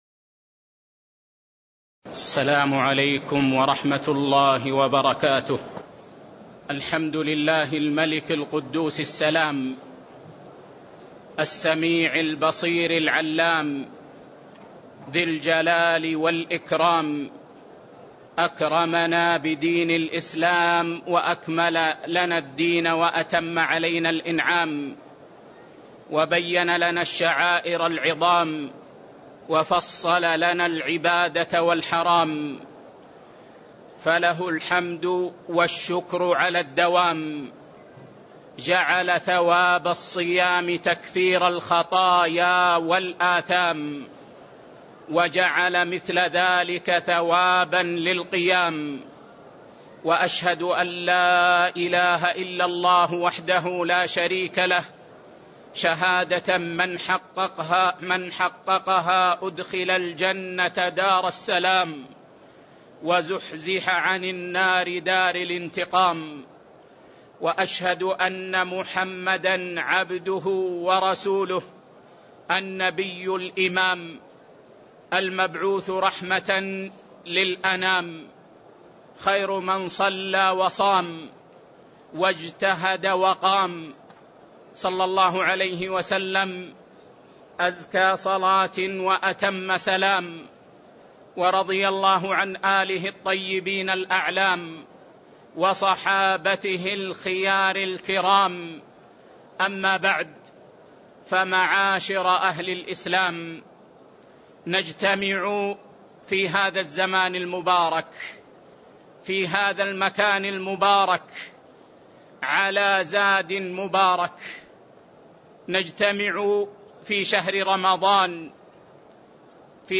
فضل رمضان - كلمة في المسجد النبوي